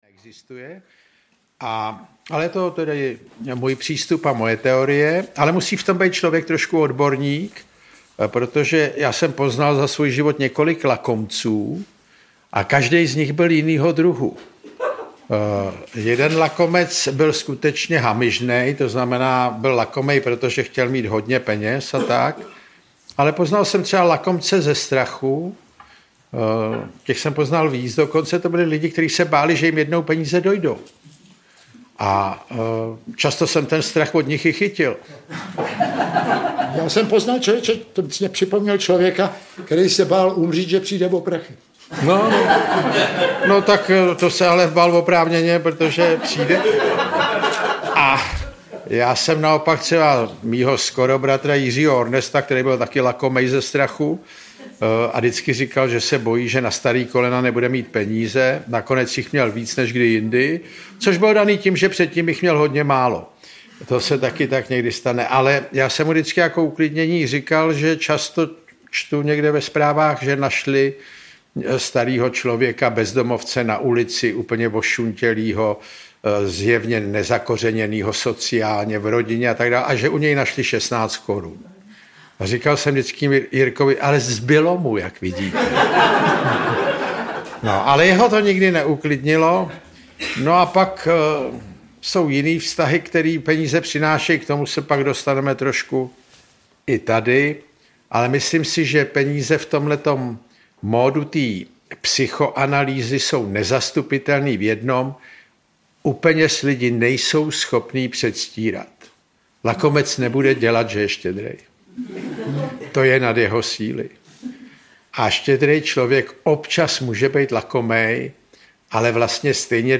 Bratři Krausové uvažují, zda by za větou „Peníze, nebo život!“ neměl být spíše otazník. Originální a autentické autorské čtení z knih Ivana Krause doplňuje řada vtipných glos a poznámek. Záznam představení z pražské Violy.
• InterpretJan Kraus, Ivan Kraus